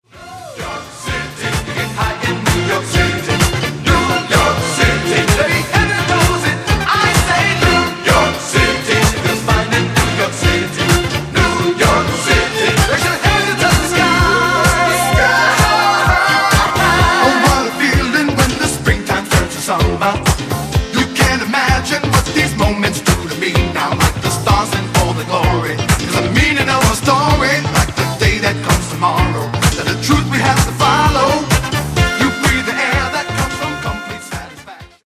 Genere:   Disco